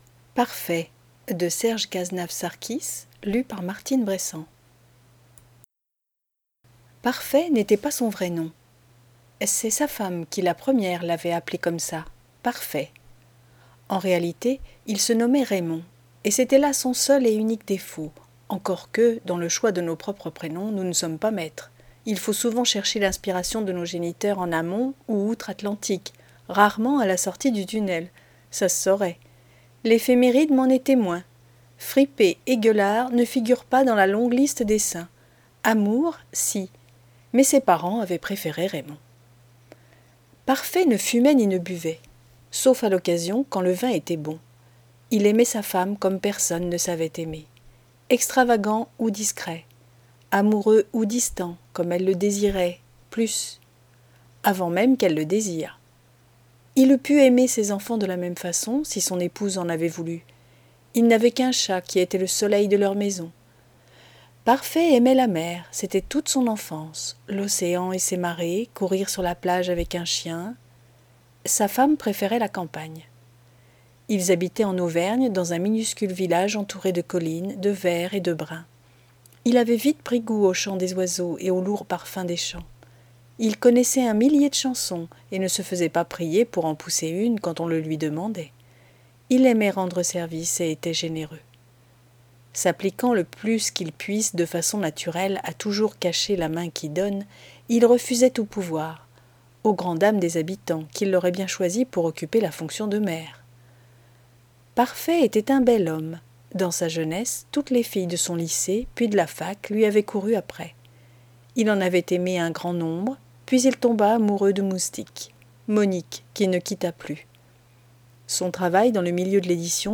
NOUVELLE